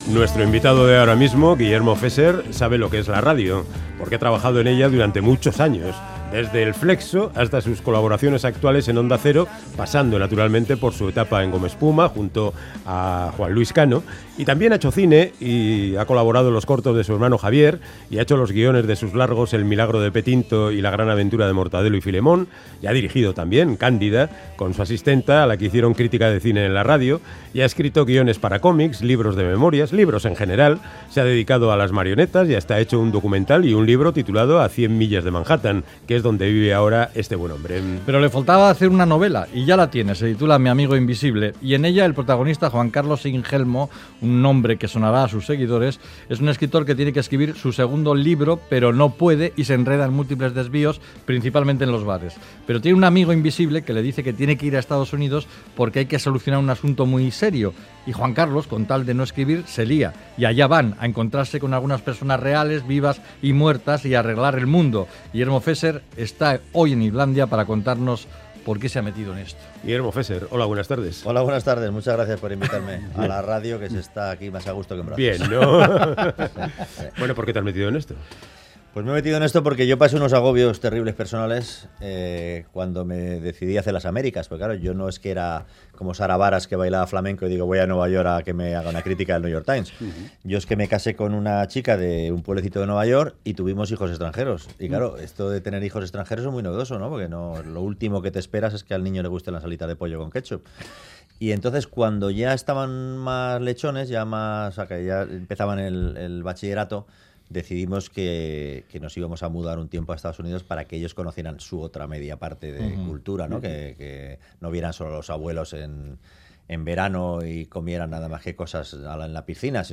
Audio: Charlamos con el humorista, guionista, director de cine y escritor Guillermo Fesser. La mitad del antiguo dúo radiofónico Gomaespuma debuta en la literatura con la novela Mi amigo invisible